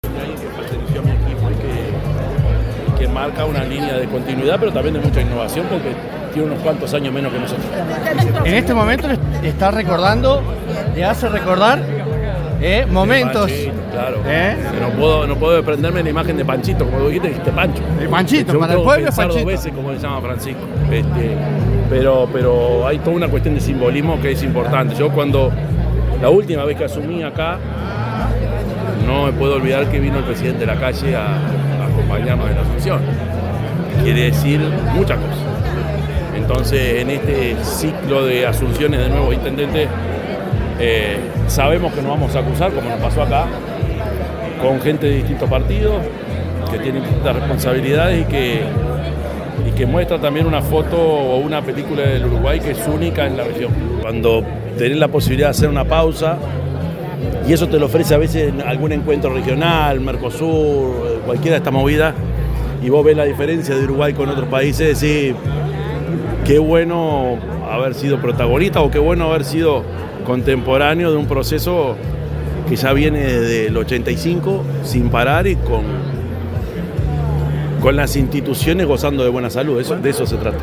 El presidente de la República, Yamandú Orsi, efectuó declaraciones ante los medios de prensa en oportunidad del acto de asunción de Francisco Legnani